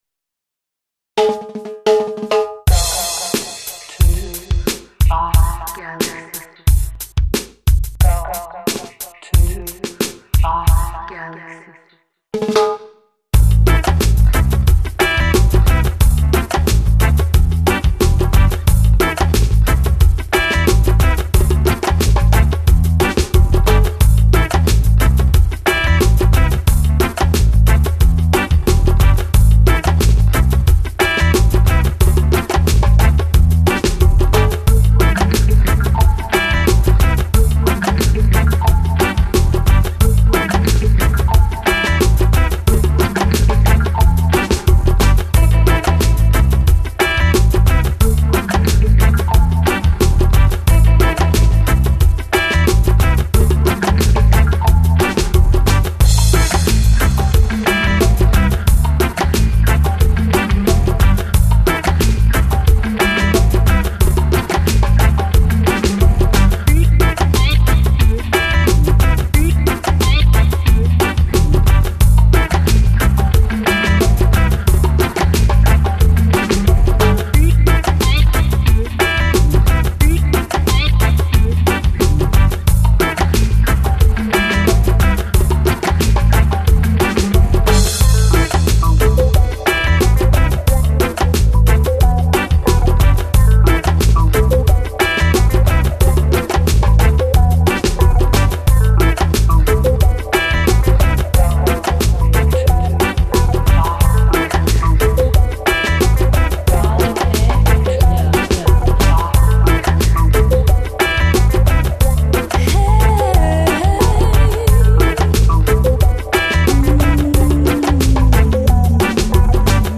son home studio